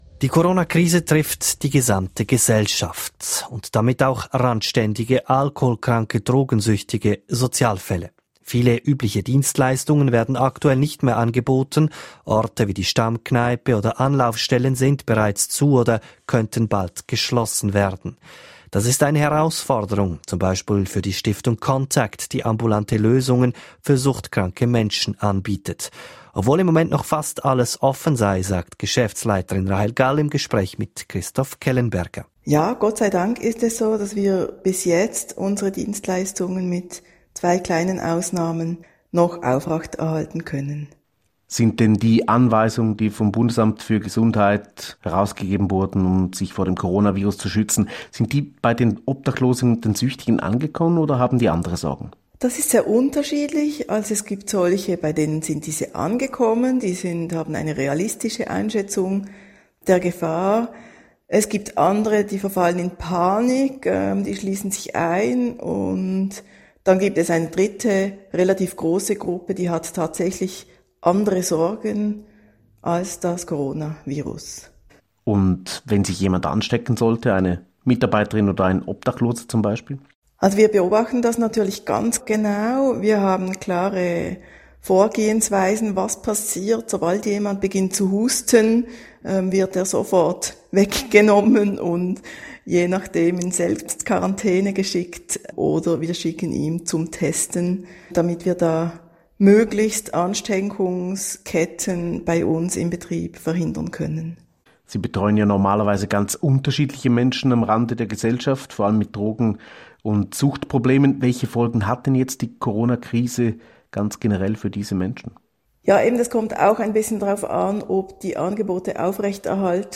SRF4 News: Interview